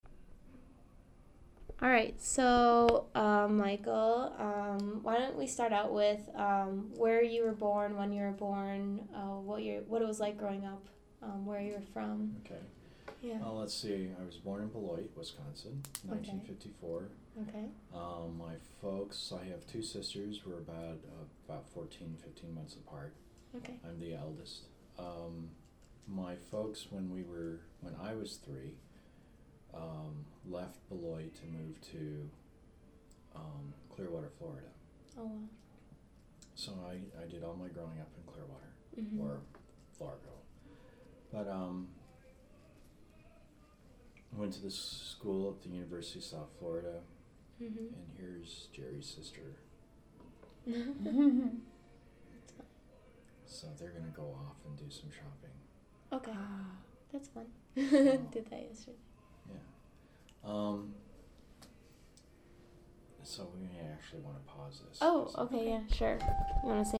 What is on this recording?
Recording, oral